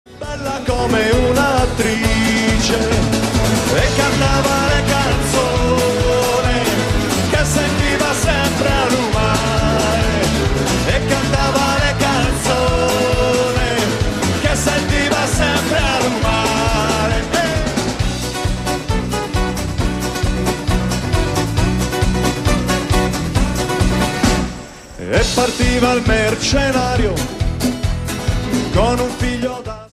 Versione live